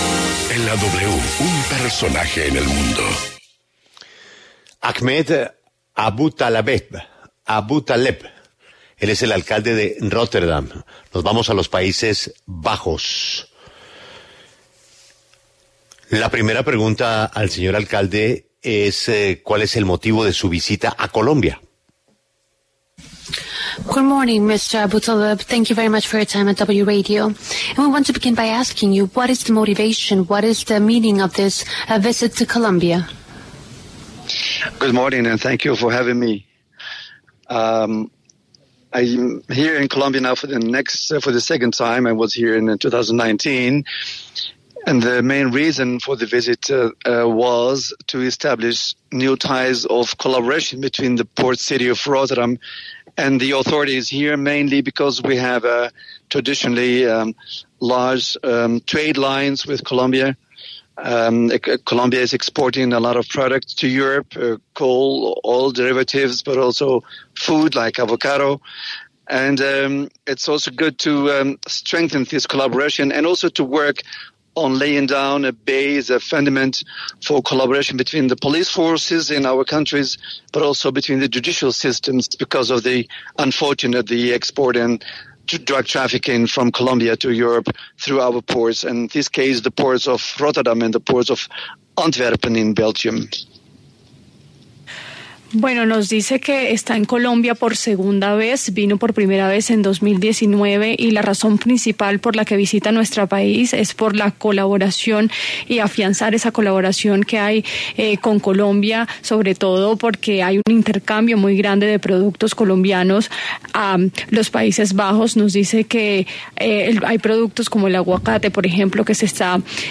Ahmed Aboutaleb, alcalde de Rotterdam, Países Bajos, habló en La W sobre su visita que irá hasta el 3 de febrero donde se discutirá sobre el mercado internacional y la lucha contra las drogas.